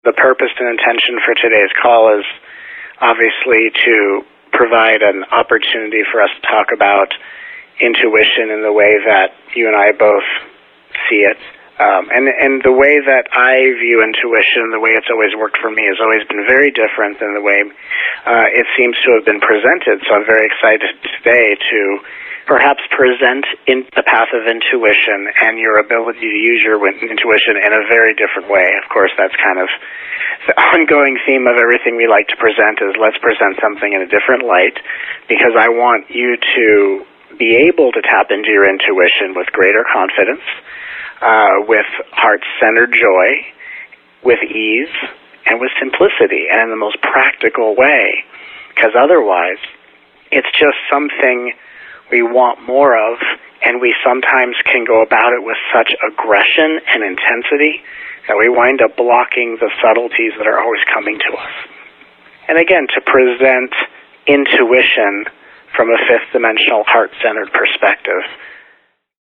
During this telegathering, you will: